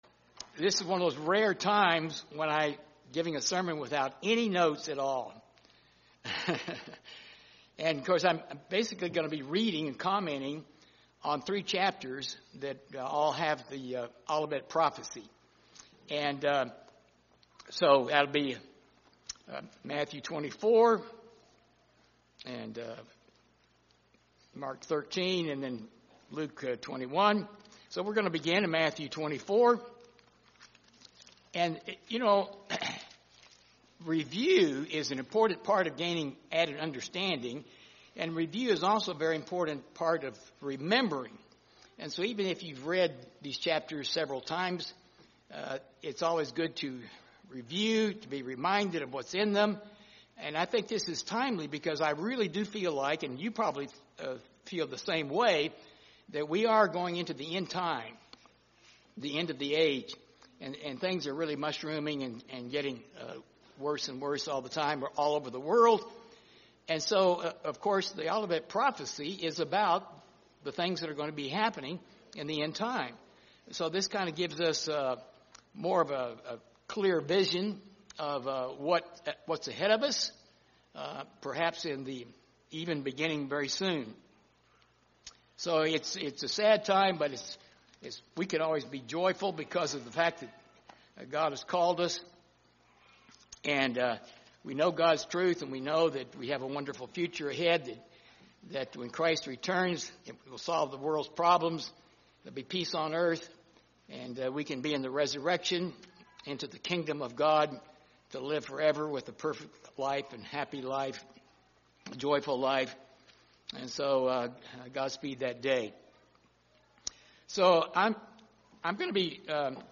This sermon covers Matthew 24, Mark 13, and Luke 21 verse by verse. One purpose is to provide a review and reminder of the content of these extremely important chapters that summarize the major prophesied events of the end time. Another purpose is to compare the points in each Gospel with the other Gospels.